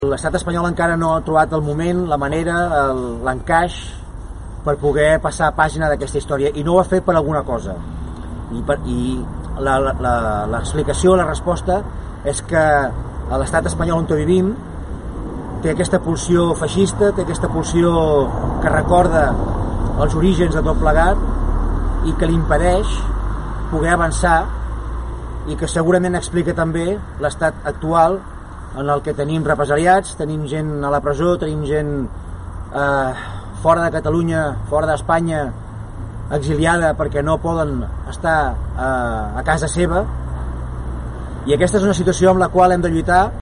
A quarts de 7 del matí, una quinzena de persones, entre elles tot el govern local, s’ha reunit entorn al monument erigit en memòria de Companys, davant de l’Skate Park. Durant l’acte, l’alcalde de Palafolls, Francesc Alemany, ha destacat la figura de Companys i ha retret a l’Estat Espanyol que encara no hagi restituït l’expresident i demanat perdó per l’afusellament de fa 80 anys a mans del franquisme.